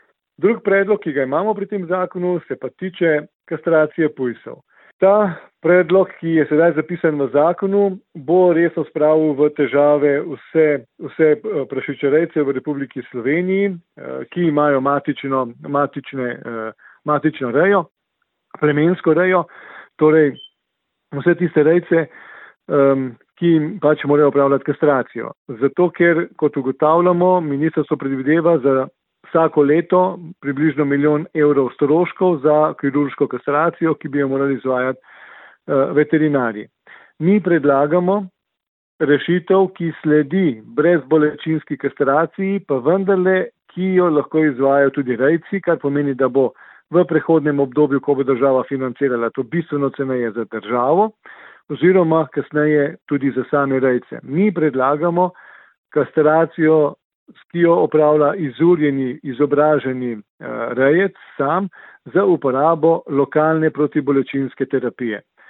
izjava Podgorsek 3 za splet.mp3